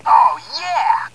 For a preview of the sound clips from the Toys R Us exclusive version, click on the three sound module bases below.
ohyeah.wav